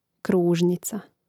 krúžnica kružnica